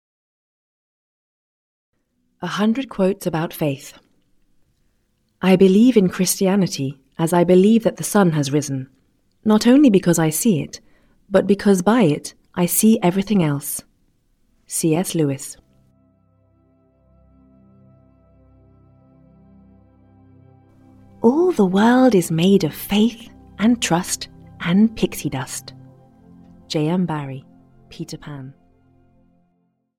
100 Quotes About Faith (EN) audiokniha
Ukázka z knihy